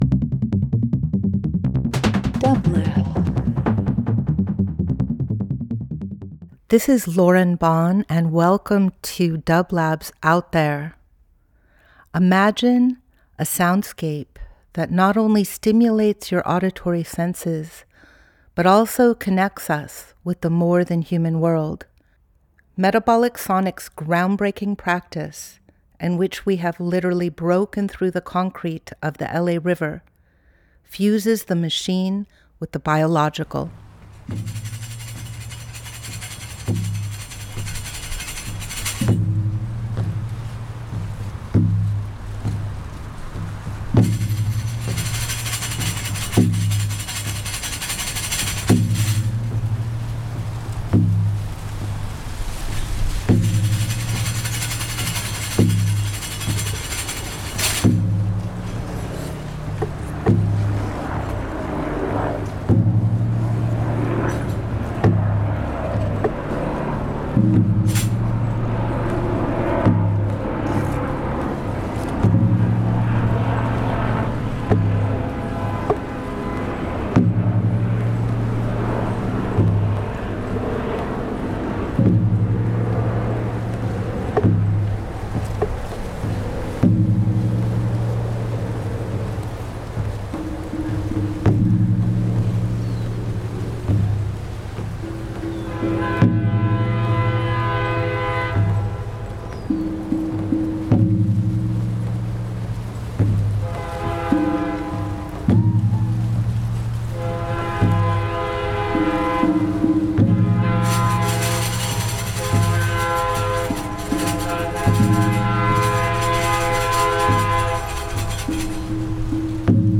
Out There ~ a Field Recording Program
Each week we present field recordings that will transport you through the power of sound. Metabolic Sonics specializes in exploring the captivating sound waves and vibrations of the web of life.
Each week we will bring field recordings of Metabolic’s current project Bending the River, and archival material from past ventures. Bending the River is an adaptive reuse of the LA River infrastructure that reimagines the relationship between Los Angeles and the river that brought it into existence.